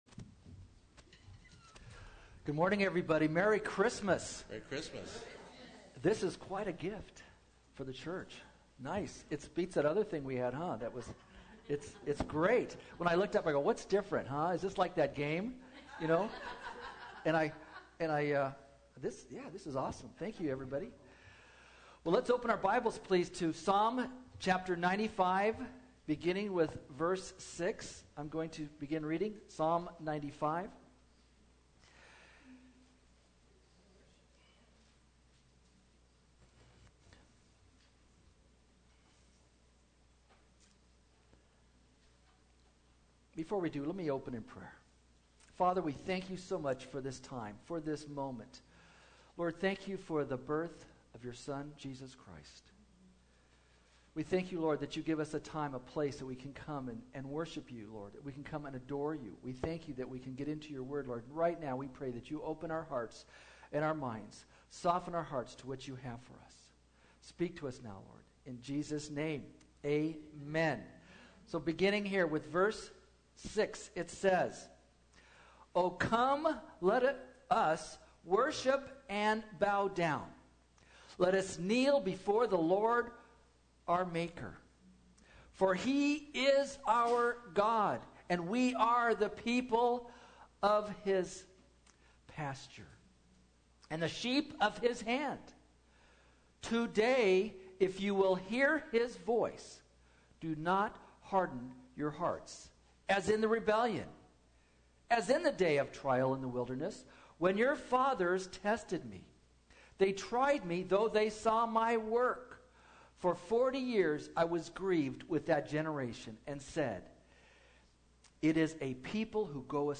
Uncategorized Service Type: Sunday Morning Christmas Rest or Christmas Wrestle?